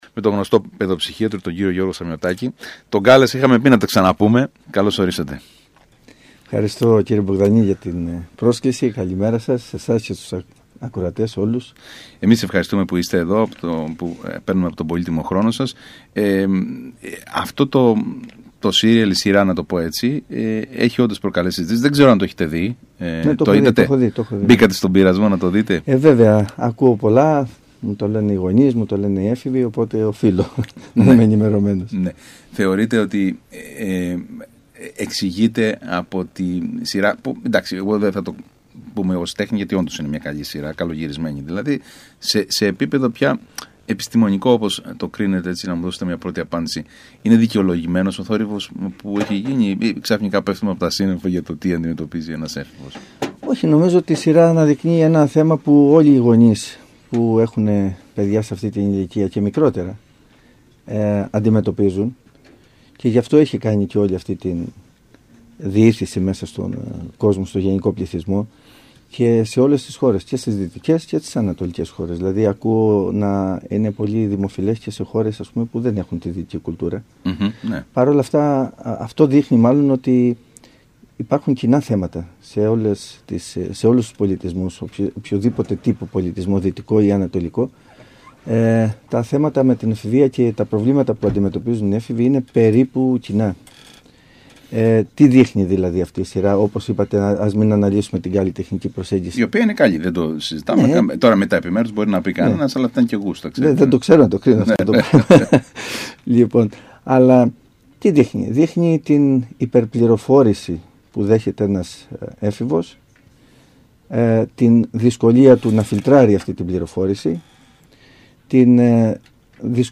μιλώντας στον ΣΚΑΙ Κρήτης